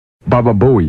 мужской голос
забавные
Забавная фраза